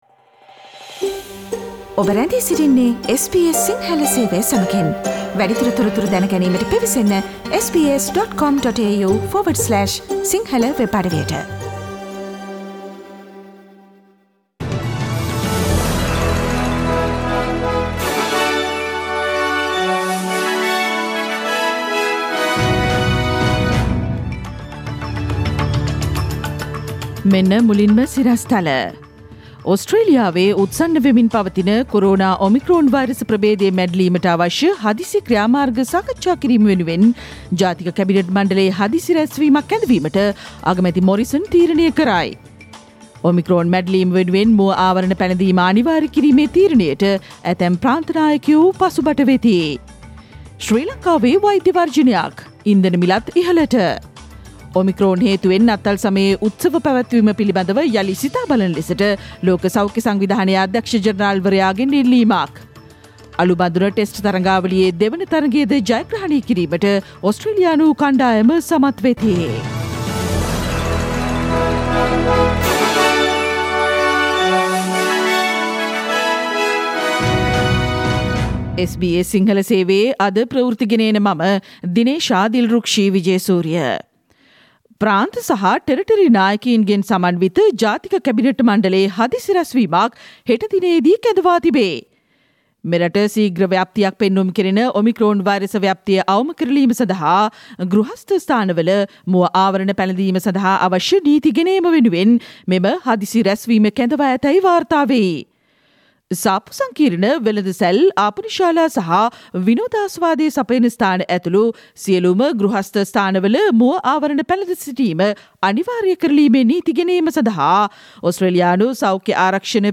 2021 දෙසැම්බර් 21වන අඟහරුවාදා SBS සිංහල ගුවන්විදුලි වැඩසටහනේ ප්‍රවෘත්ති ප්‍රකාශයට සවන්දෙන්න ඉහත චායාරූපය මත ඇති speaker සලකුණ මත click කරන්න